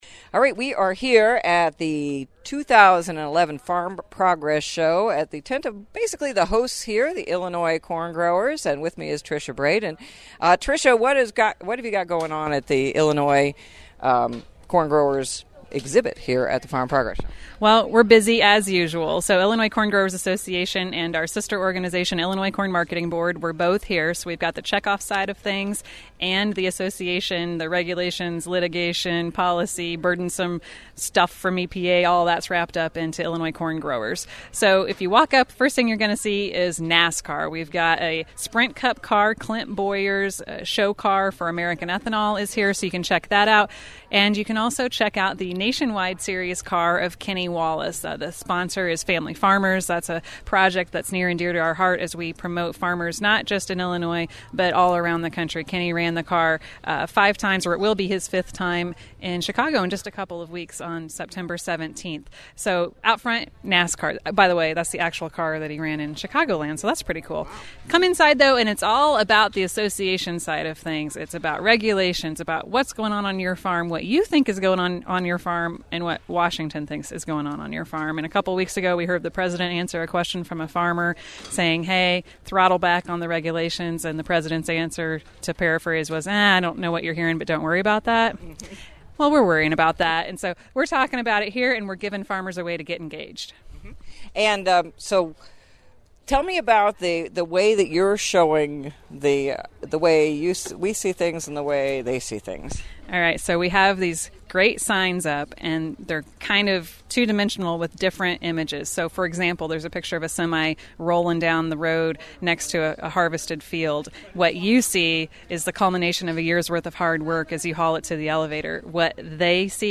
Illinois Corn Growers at Farm Progress Show